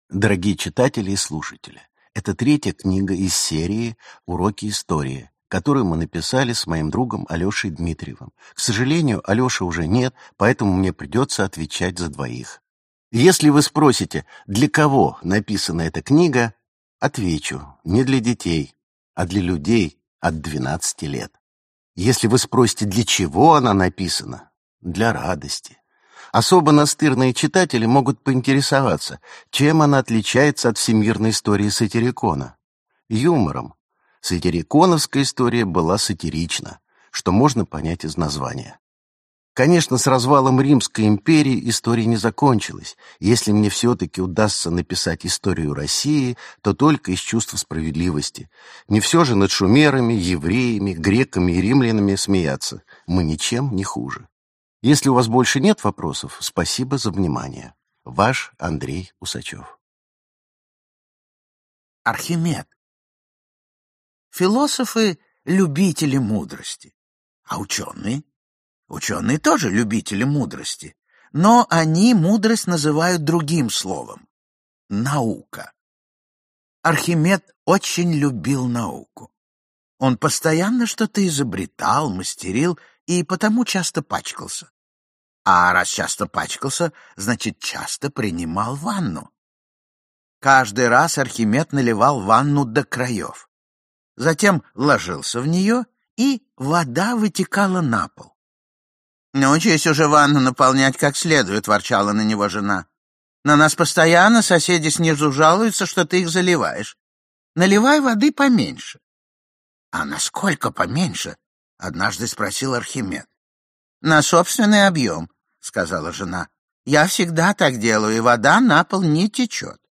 Аудиокнига Цезарь – человек и салат. Античная история | Библиотека аудиокниг
Античная история Автор Андрей Усачев Читает аудиокнигу Ефим Шифрин.